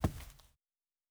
Footstep Carpet Walking 1_02.wav